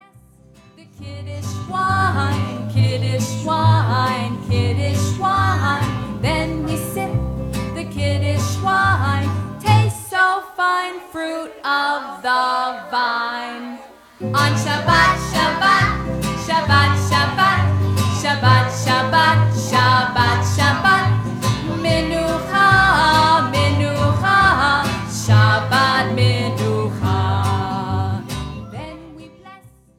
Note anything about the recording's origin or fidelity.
Recorded before an audience of children